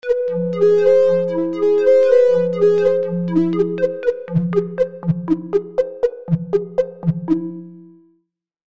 List of 32 Preset Sounds / Demo